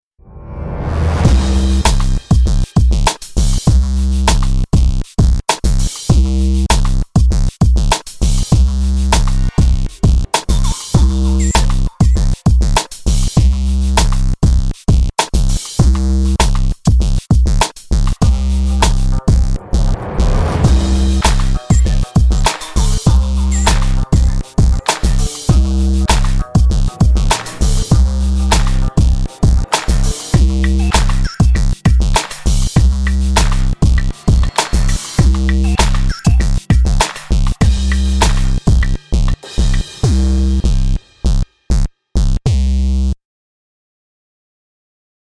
Category: Production Music
genre: Hip Hop, TV Soundtrack
moods: Dramatic, Rough, Dark/Sinister
tempo: medium
intensity: hot